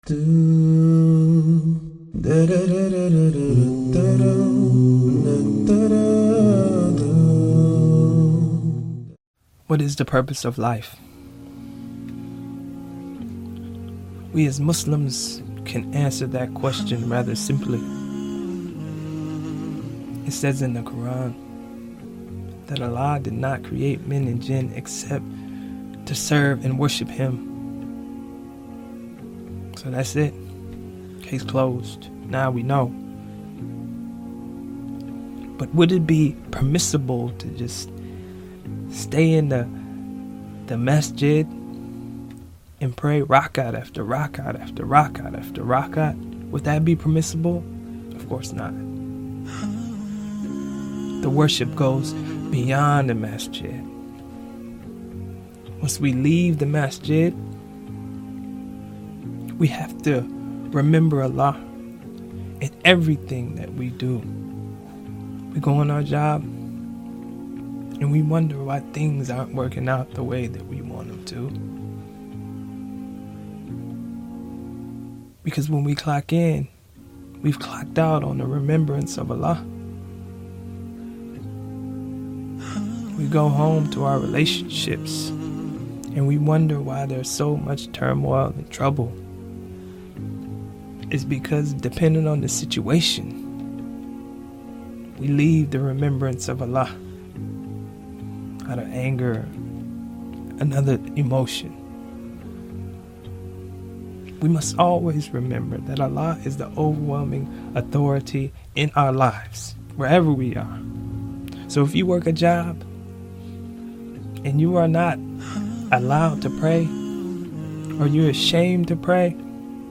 The background music is 100% halal nasheed.